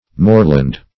moreland \more"land\ (m[o^]r"l[a^]nd), n.